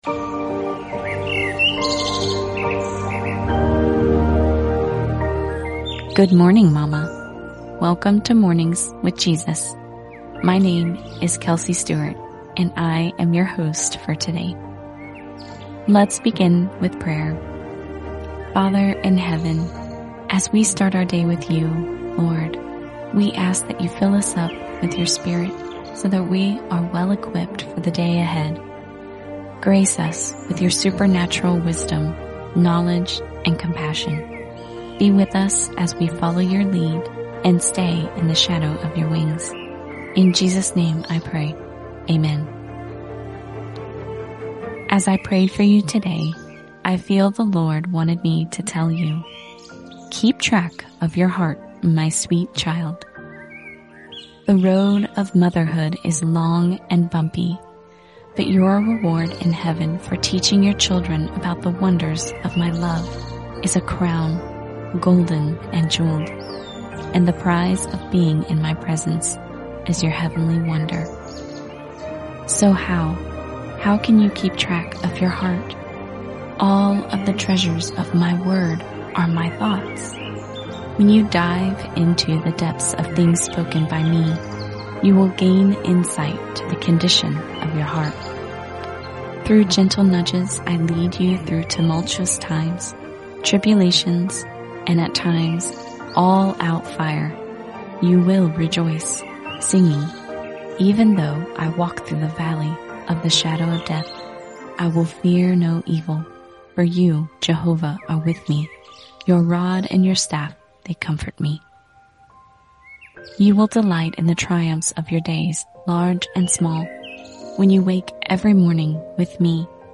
Mornings With Jesus: A Five- Day Audio Devotional Plan for Moms